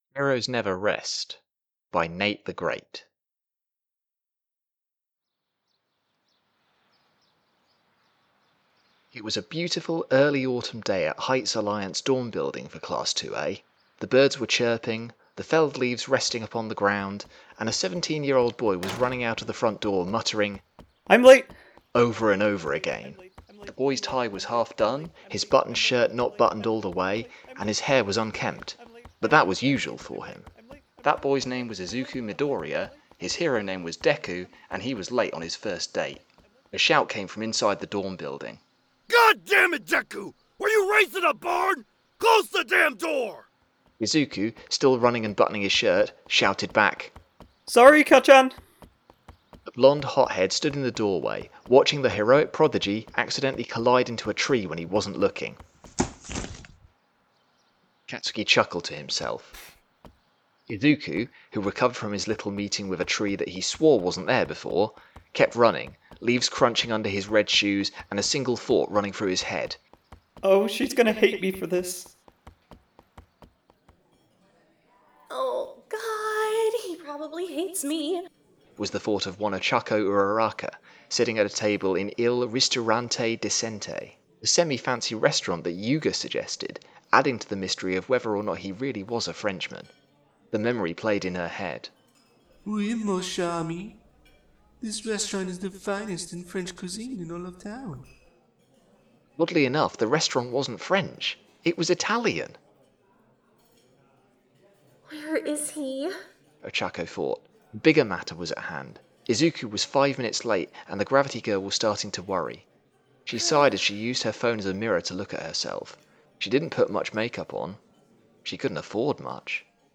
Heroes Never Rest | Podfic
" Power up yells " by AmeAngelofSin This sound is licensed under CC BY 4.0 .
" Amusement arcade-01.wav " by KeyKrusher This sound is licensed under CC BY 4.0 .